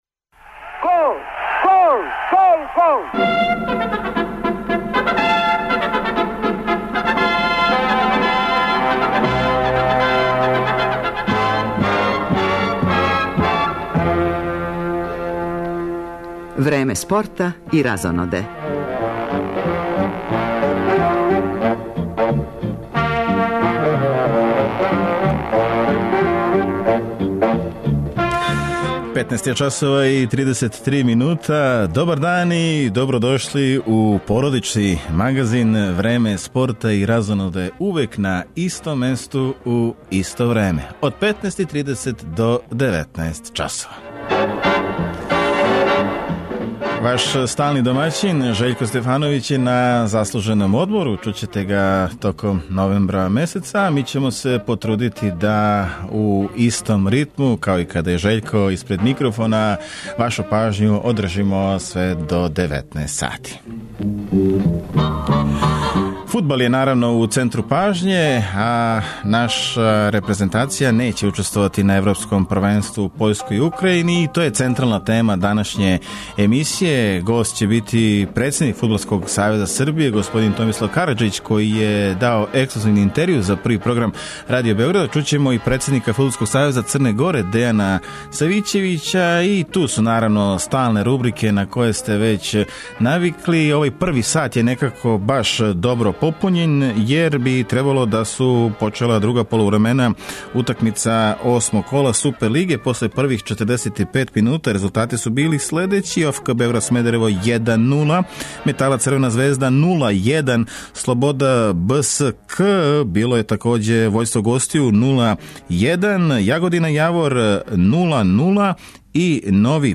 Преносимо утакмице осмог кола Супер лиге.